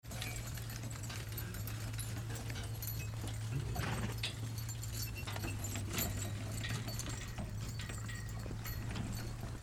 Звук упряжки лошадей